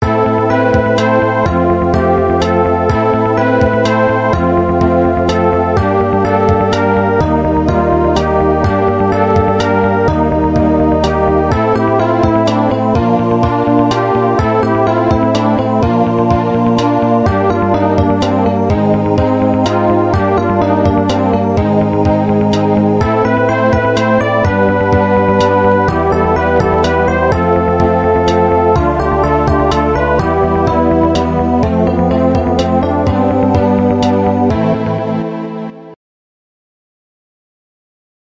One of my most faithful recreations of in-game music.